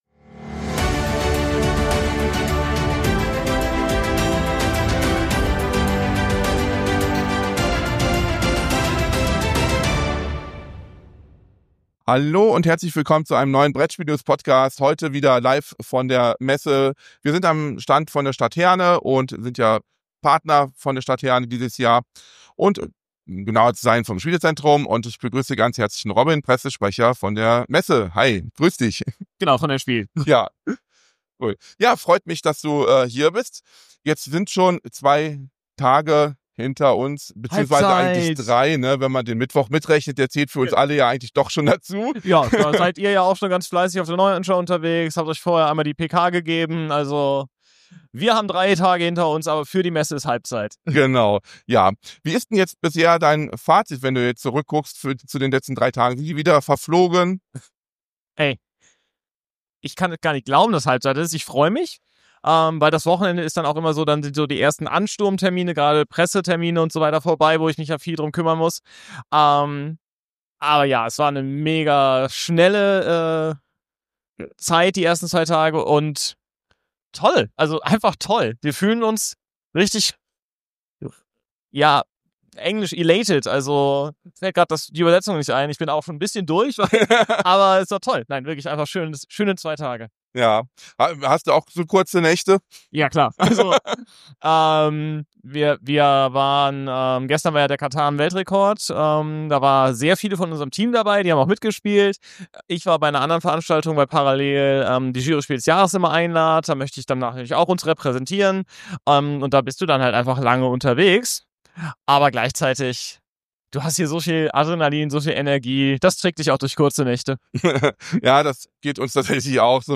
In dieser neuen Episode des Brettspiel-News-Podcasts nehmen wir Sie mit auf die spannende Reise der diesjährigen Spielemesse in Essen, wo wir direkt am Stand des Spielezentrums Herne sind.